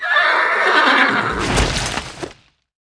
萧萧